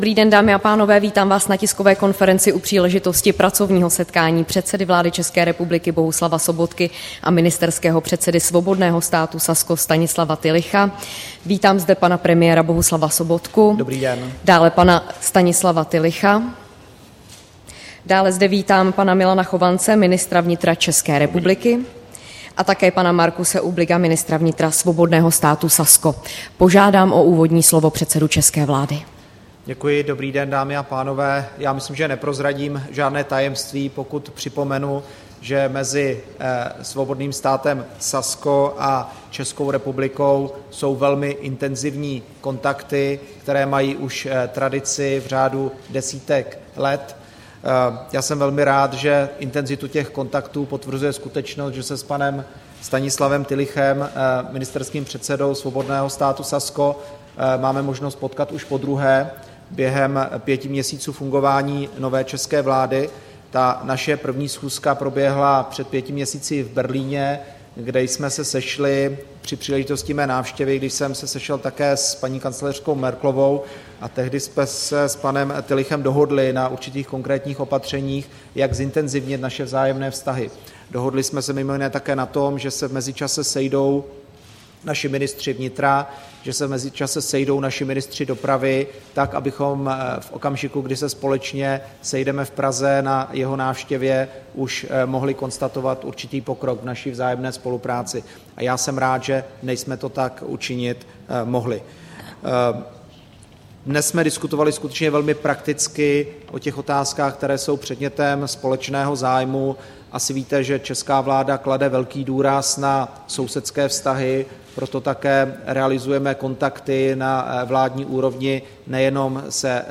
Tisková konference po setkání s ministerským předsedou Saska Stanislawem Tillichem